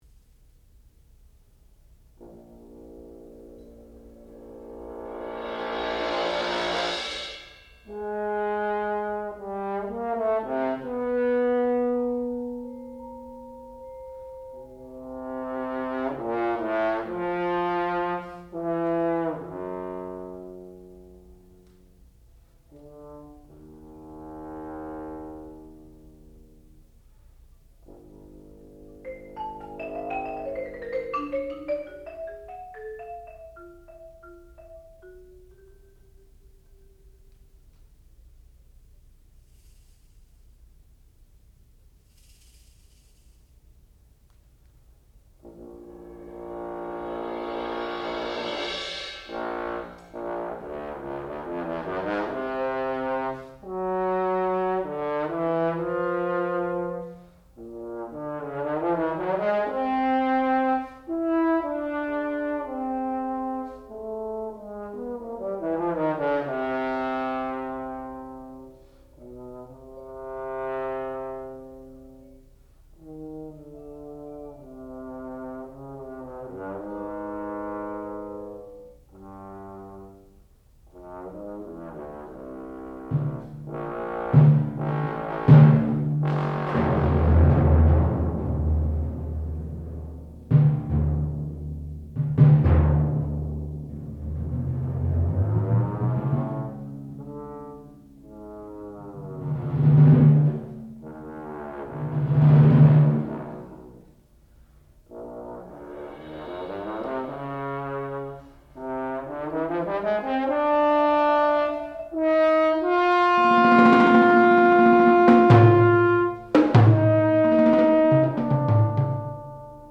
Control/React (1979) for Bass Trombone and Percussion
sound recording-musical
classical music
Master Recital
bass trombone